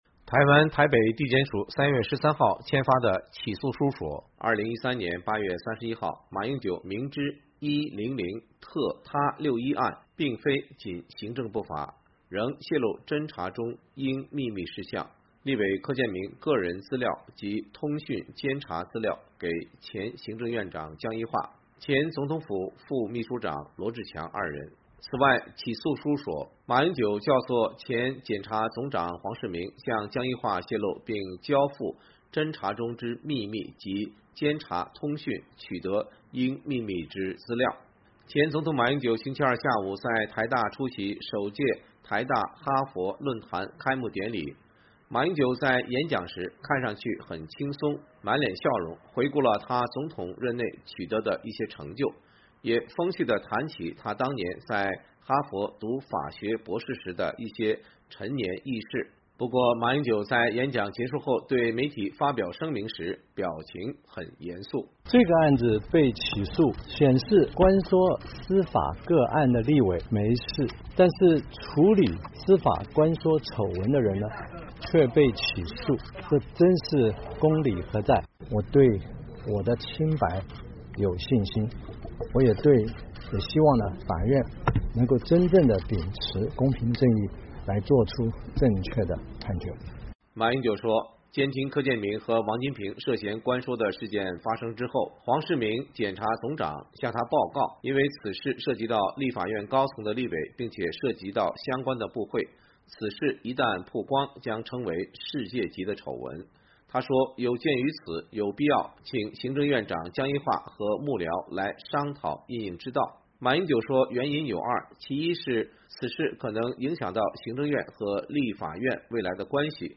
马英九向媒体发表对被起诉的声明